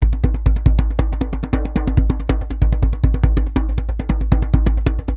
PERC 06.AI.wav